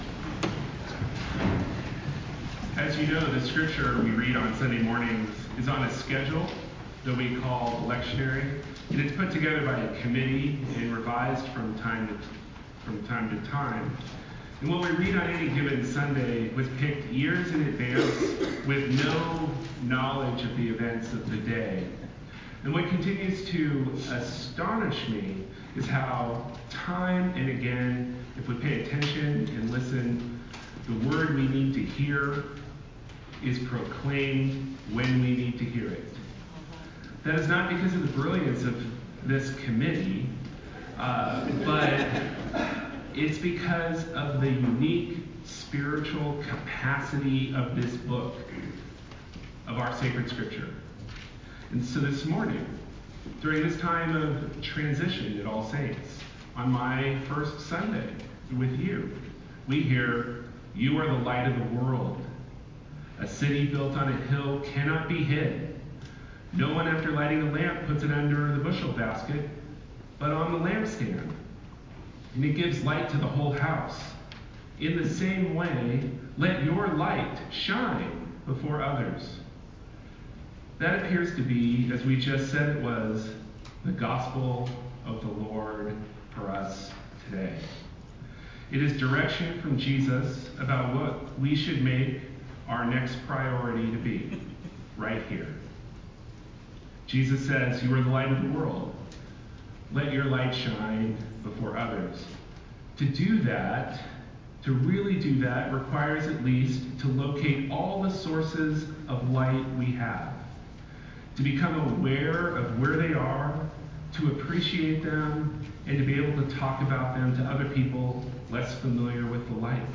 Sermon: You are the light of the World – February 9, 2020 – All Saints' Episcopal Church